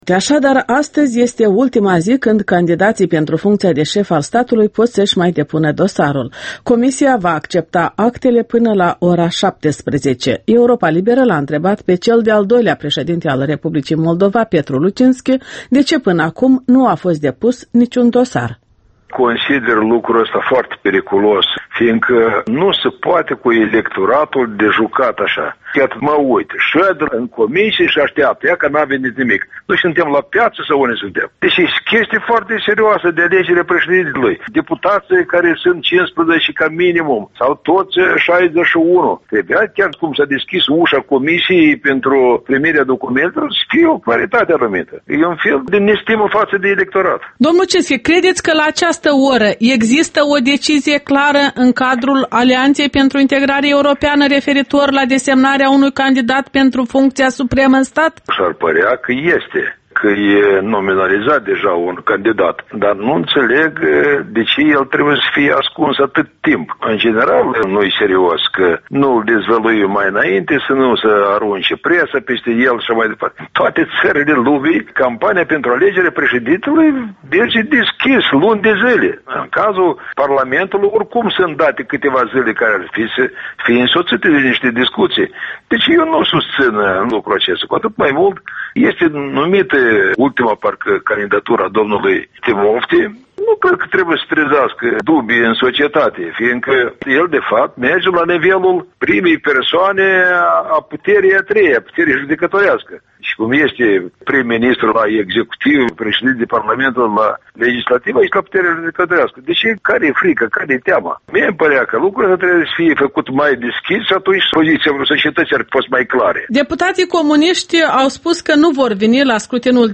Interviul dimineții la Europa Liberă: cu fostul președinte Petru Lucinschi